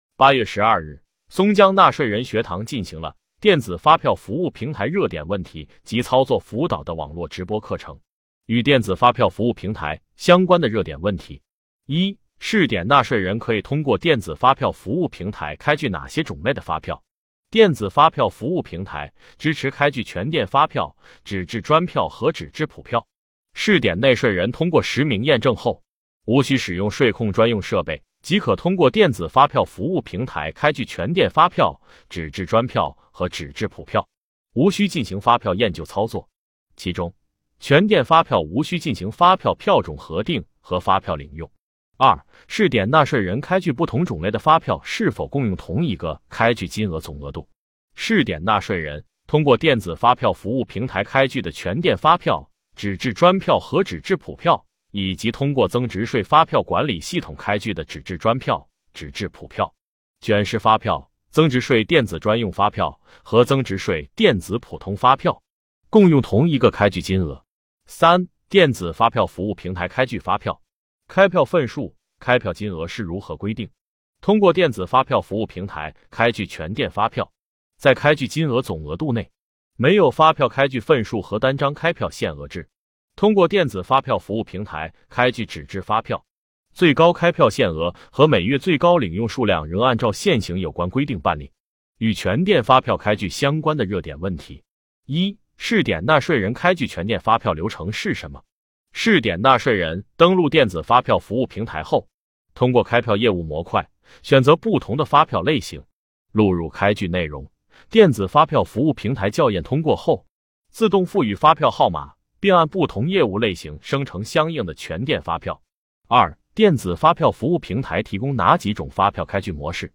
目前，松江区税务局通过网络直播的形式开展纳税人学堂。